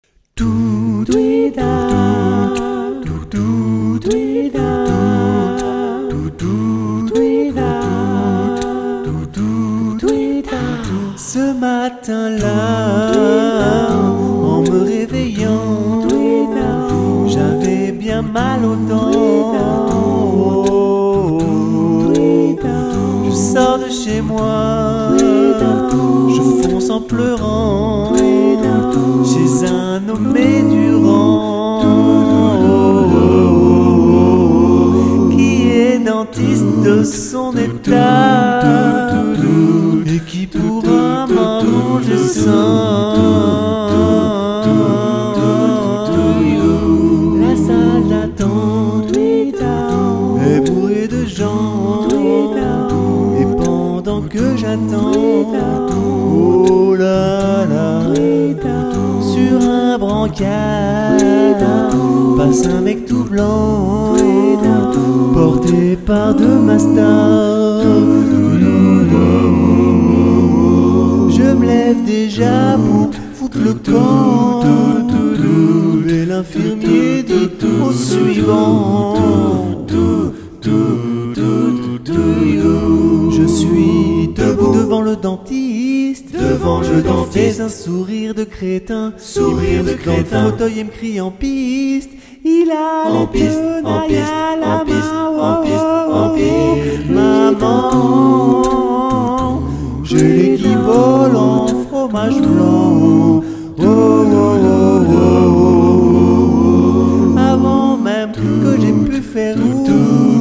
Atelier Chant Polyphonique A Capella Adultes (Nouveau !!!)
Il s'agit d'une approche moderne du chant chorale, incluant des voix mélodiques, rythmiques, des percussions vocales (beat box) et corporelles.